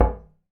glass_0.ogg